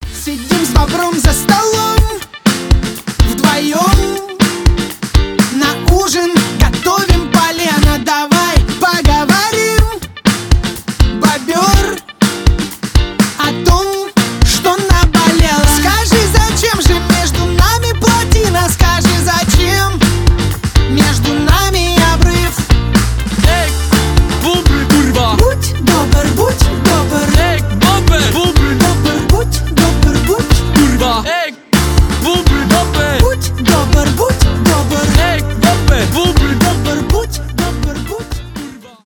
поп
смешные , веселые , прикольные